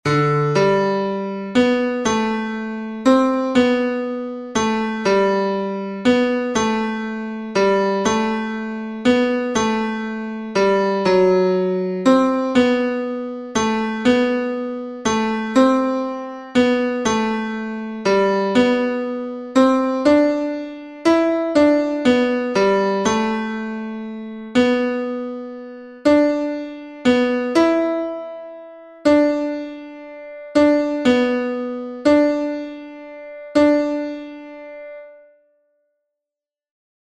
3e couplet tenors-mp3 29 septembre 2020